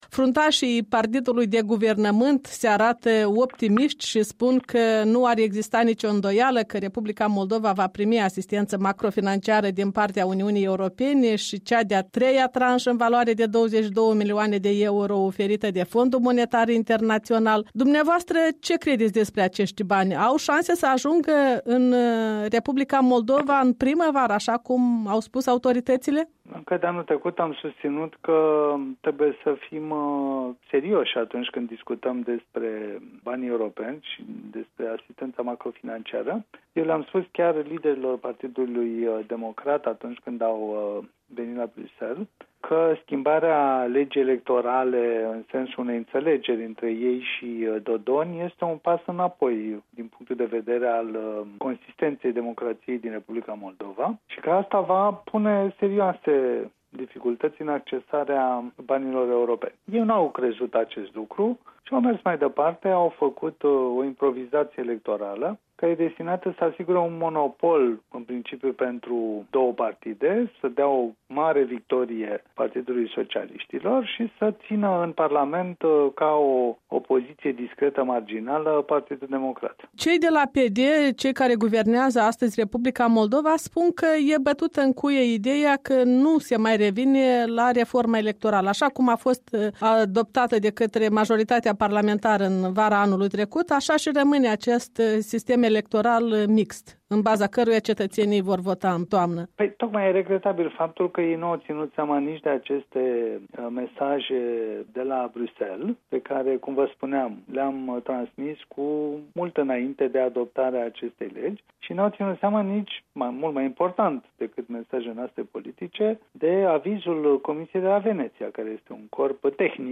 Un interviu cu europarlamentarul român Cristian Preda.